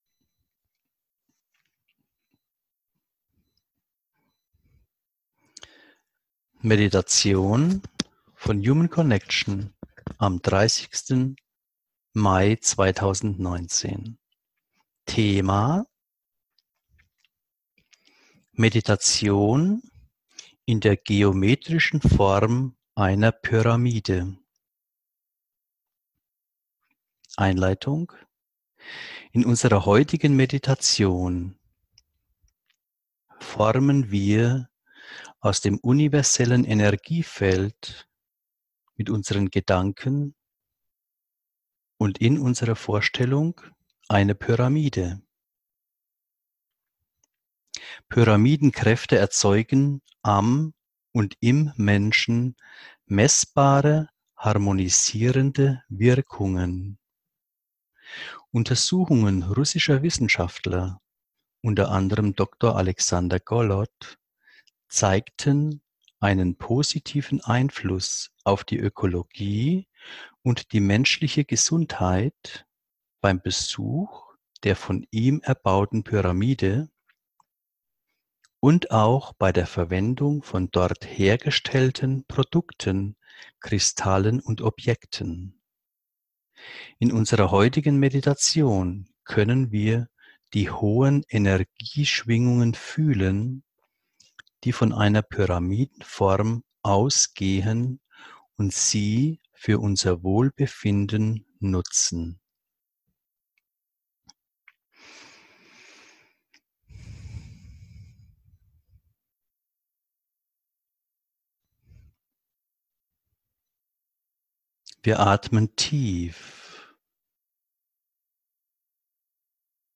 Meditation in der geometrischen Form einer Pyramide